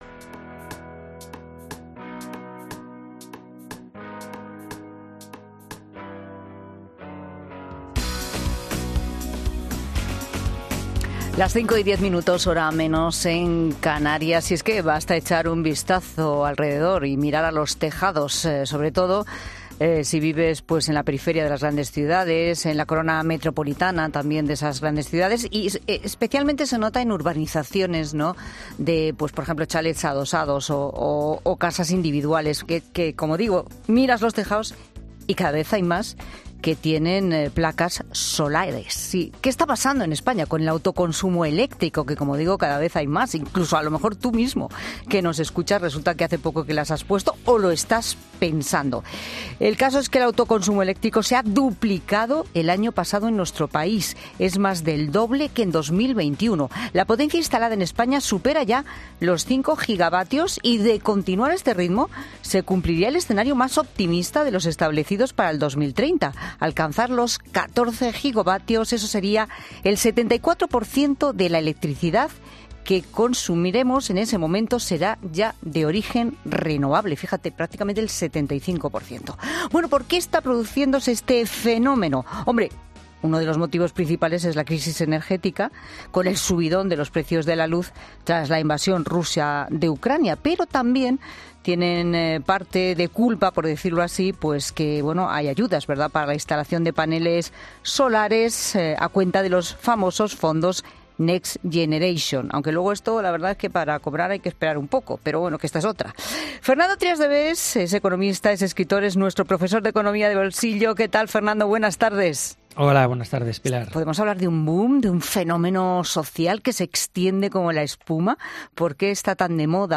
El profesor Fernando Trías de Bes explica en La Tarde de COPE cómo será el autoconsumo del futuro